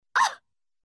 PLAY that’s disgusting sound effect
Play, download and share disgust original sound button!!!!